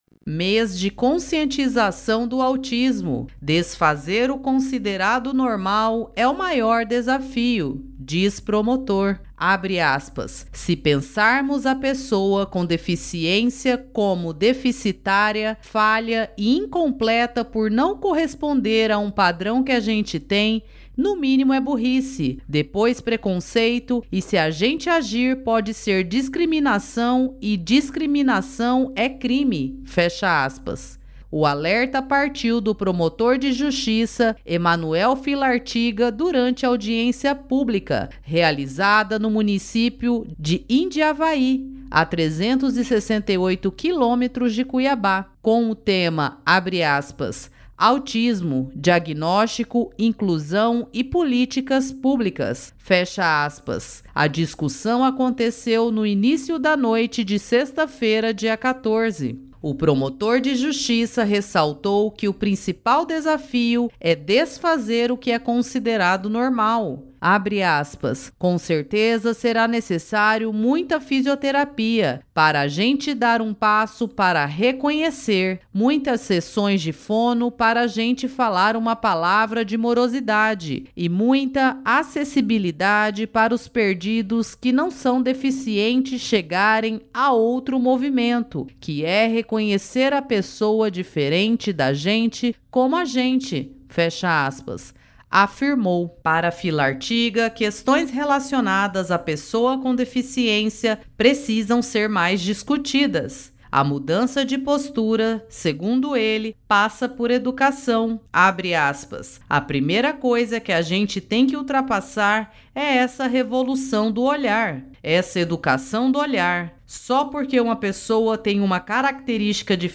O alerta partiu do promotor de Justiça Emanuel Filartiga durante audiência pública realizada no município de Indiavaí (a 368 km de Cuiabá), com o tema “Autismo: diagnóstico, inclusão e políticas públicas”.